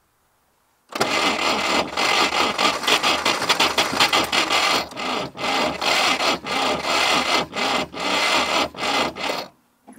6. Звук стартера Волги, машина не заводится
gaz24-ne-zavoditsia.mp3